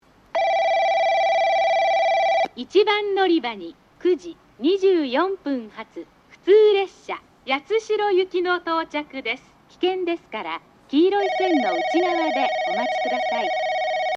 1番のりば接近放送（八代行き） 放送はJACROS簡易詳細型で、接近ベルが鳴ります。
スピーカーはJVCラインアレイです。
なお駅前は交通量が多いので、雑音が入りやすいです。
※接近ベルは一部省略しております。